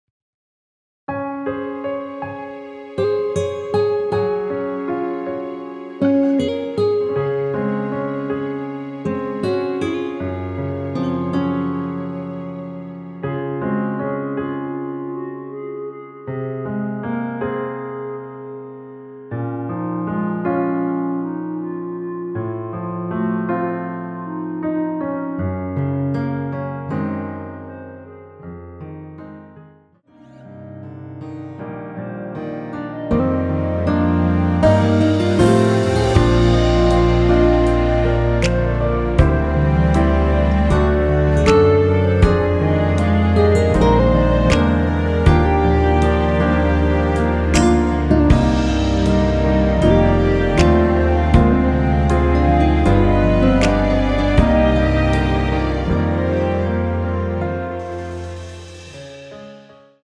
원키에서(-3)내린 멜로디 포함된 MR입니다.(미리듣기 확인)
Db
앞부분30초, 뒷부분30초씩 편집해서 올려 드리고 있습니다.
중간에 음이 끈어지고 다시 나오는 이유는